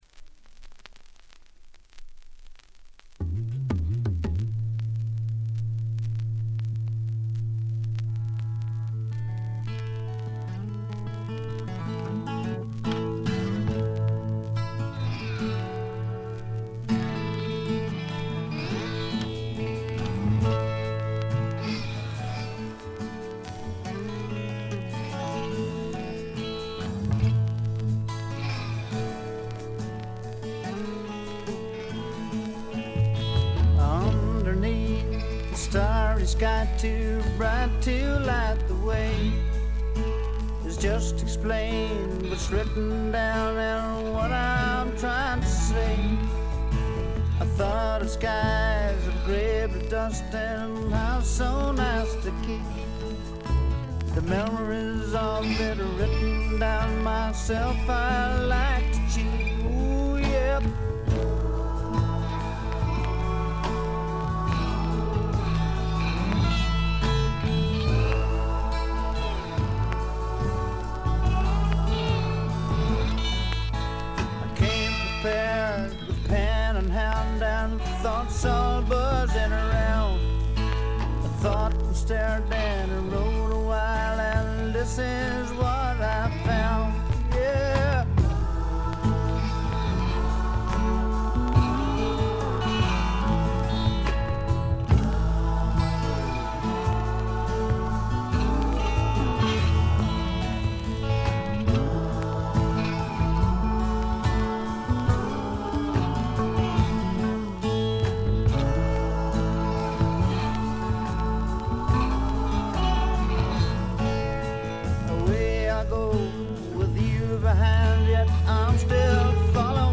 バックグラウンドノイズ、チリプチ、プツ音等多め大きめですが、鑑賞を妨げるほどではなく普通に聴けるレベルと思います。
内容は笑っちゃうぐらい売れなさそうな激渋スワンプ。
試聴曲は現品からの取り込み音源です。